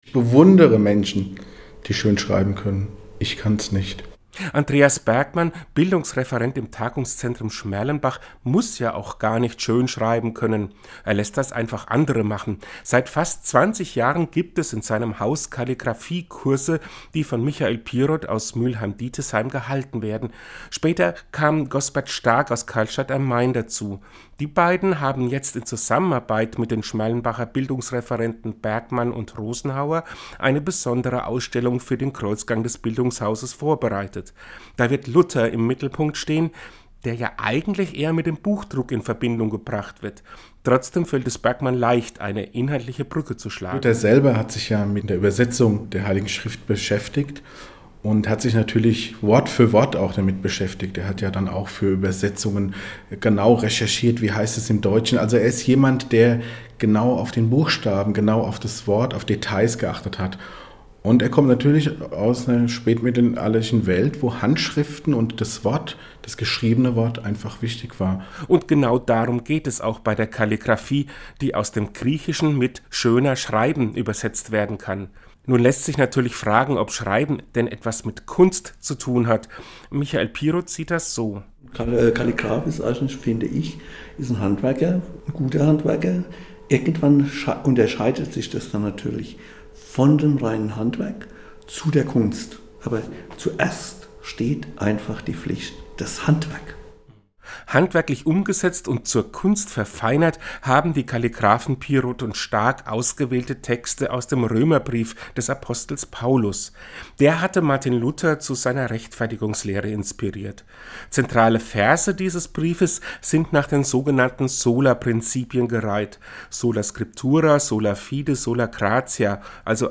Seinen Radiobeitrag finden Sie unten als Download Am Sonntag, den 8.